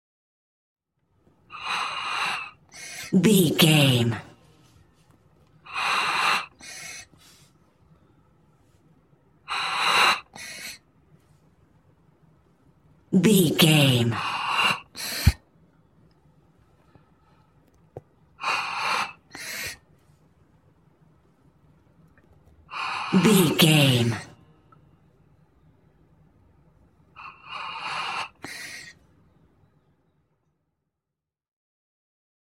Emergency Bdm Hand Inhaler
Sound Effects
chaotic
anxious
dramatic